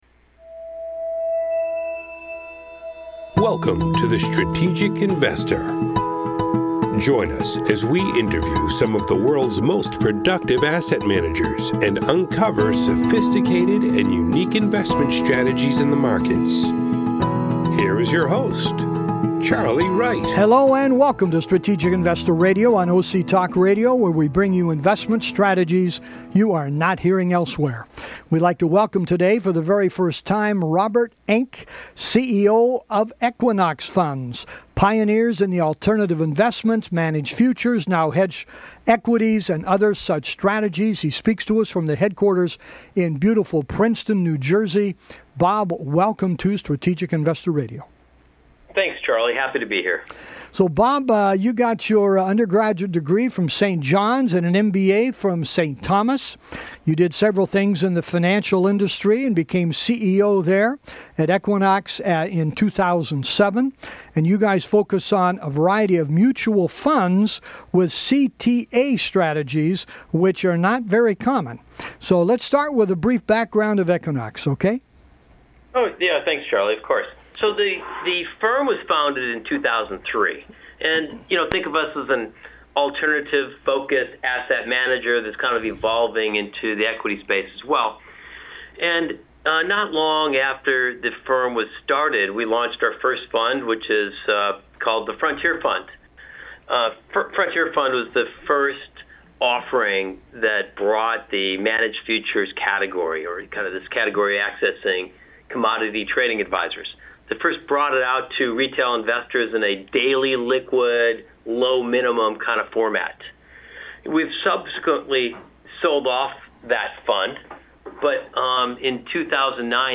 Anyone looking for diversification and possible enhanced returns thru Commodities should find this interview to be very interesting.